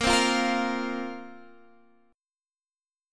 Bbsus2 chord